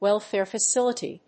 welfare+facility.mp3